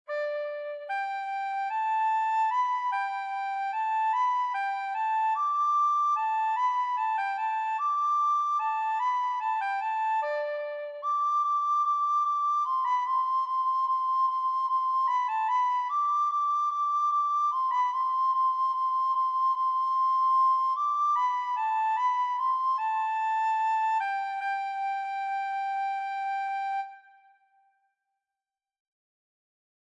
Captura da partitura para frauta da obra Música Acuática de G. F. Händel
Musica_acuatica_lenta.mp3